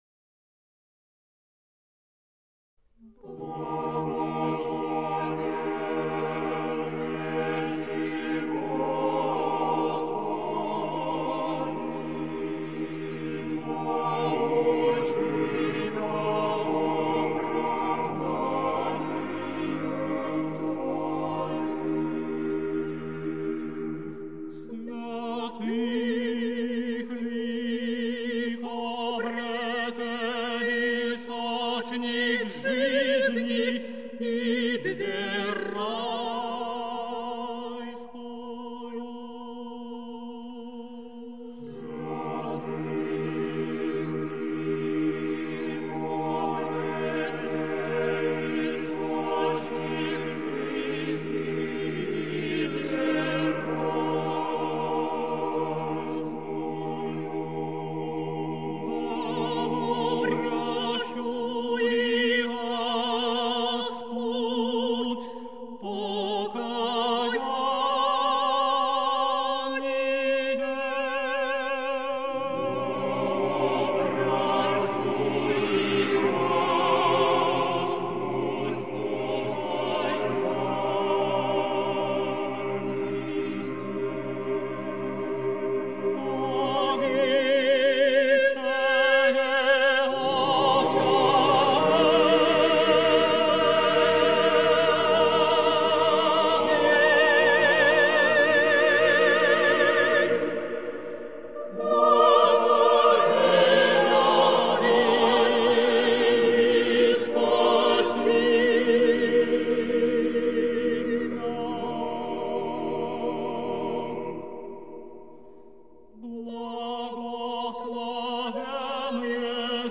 лирико-колоратурное сопрано
Ансамбль духовной музыки "Кант",